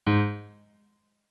MIDI-Synthesizer/Project/Piano/24.ogg at 51c16a17ac42a0203ee77c8c68e83996ce3f6132